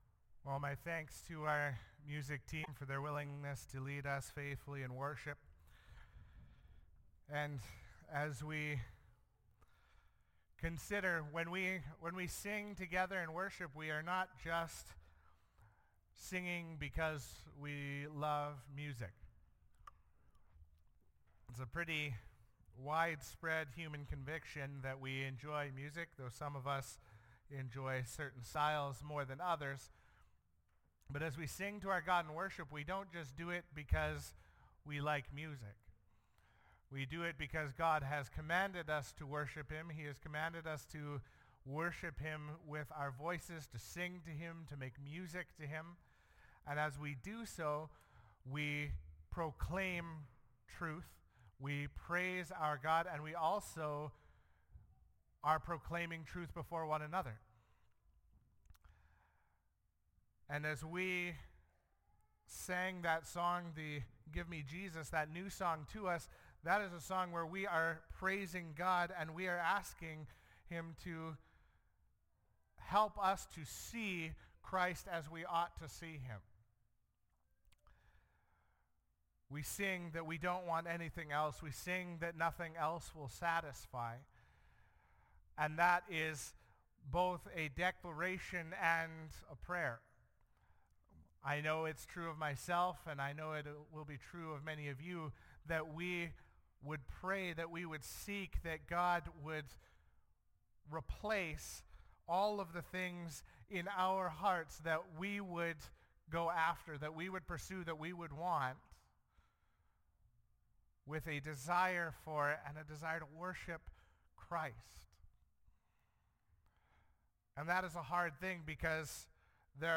Sermons | Elk Point Baptist Church